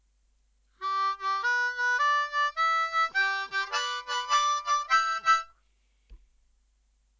Add some tongue slaps to the octaves for extra crunch.
Over the I chord